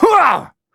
Kibera-Vox_Attack2.wav